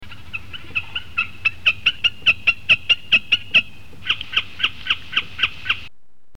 Bécassine des marais
Gallinago gallinago